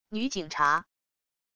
女警察wav音频